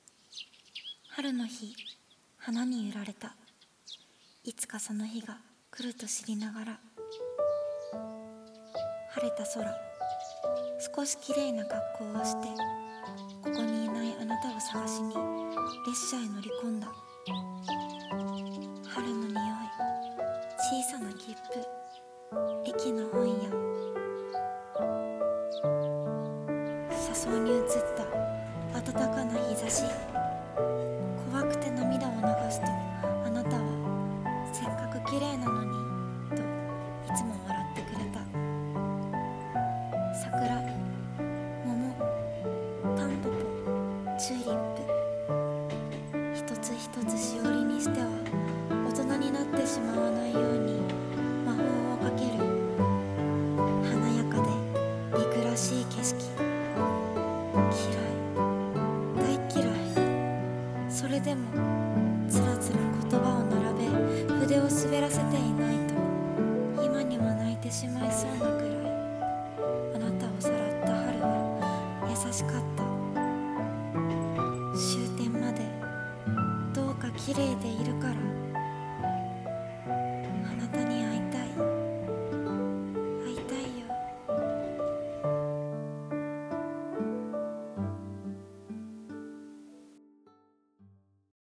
朗読「 春の切符